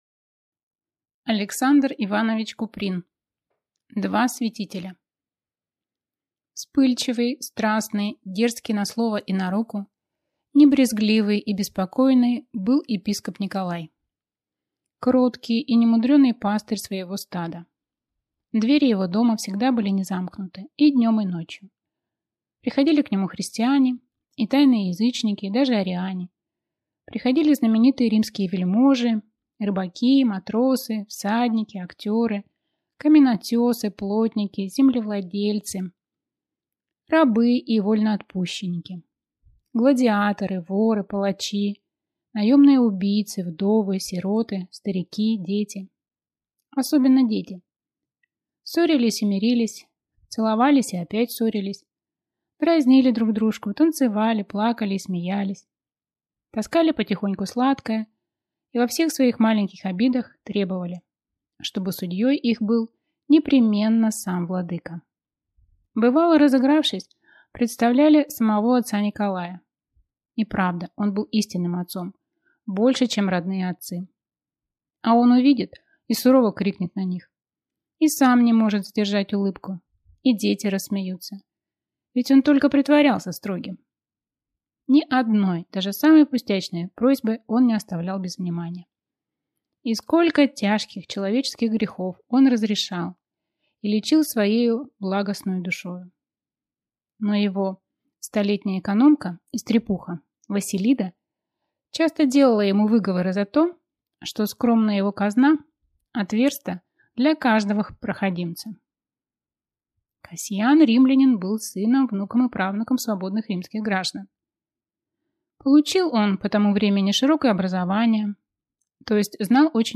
Аудиокнига Два святителя